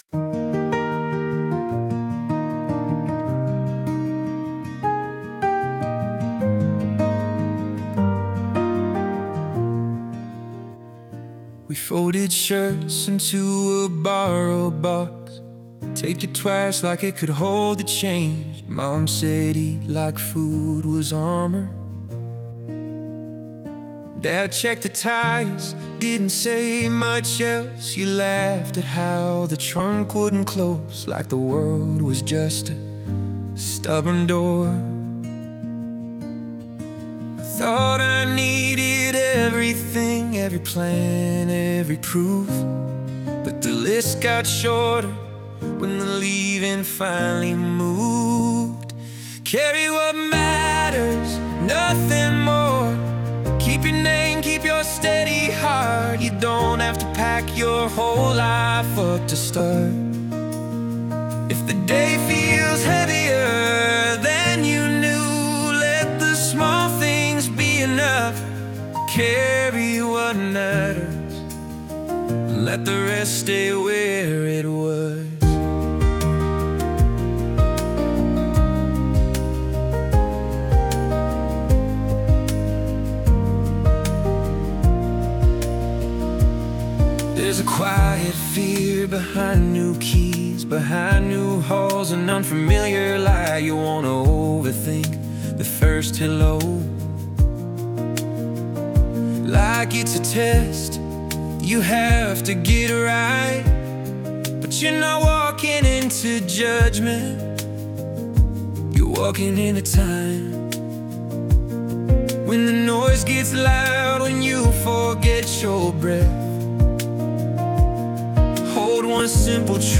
Discover the Heartbeat of Acoustic Storytelling